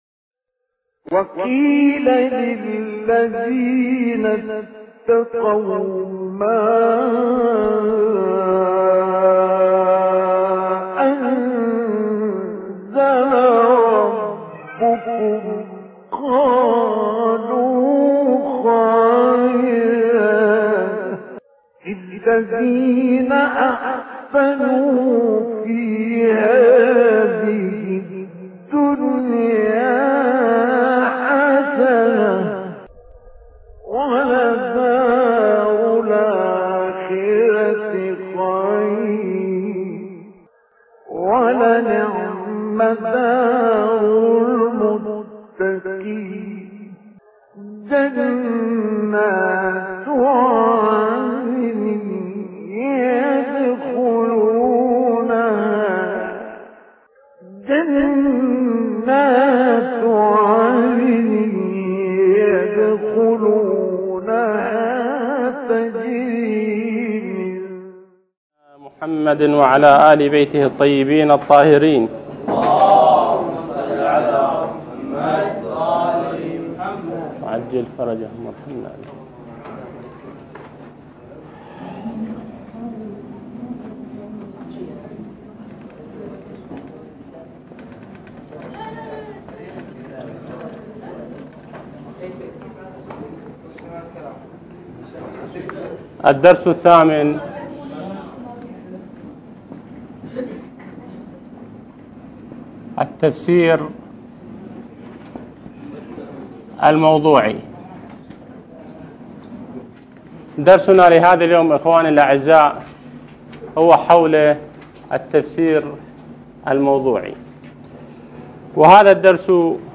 الدرس الثامن التفسير الموضوعي - لحفظ الملف في مجلد خاص اضغط بالزر الأيمن هنا ثم اختر (حفظ الهدف باسم - Save Target As) واختر المكان المناسب